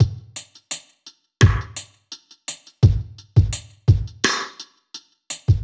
Index of /musicradar/dub-drums-samples/85bpm
Db_DrumKitC_Dry_85-01.wav